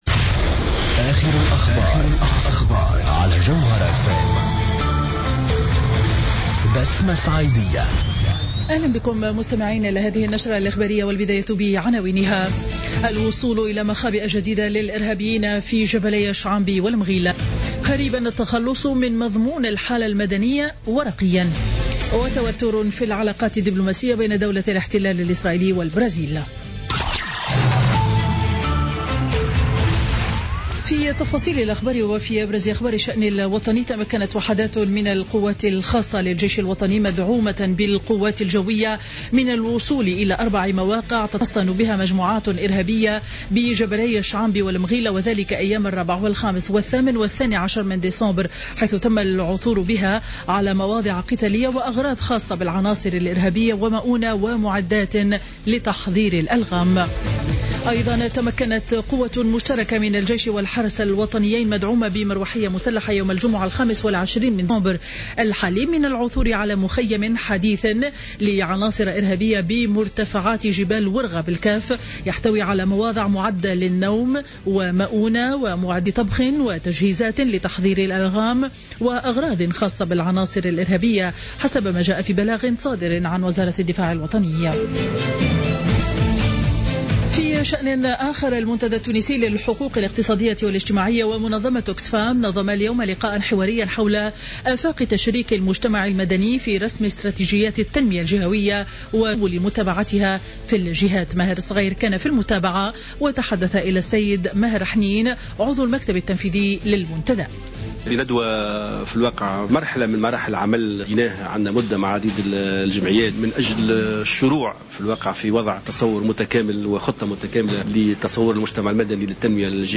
نشرة أخبار منتصف النهار ليوم الإثنين 28 ديسمبر 2015